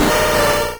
Cri de Mackogneur dans Pokémon Rouge et Bleu.